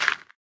Sound / Minecraft / dig / gravel2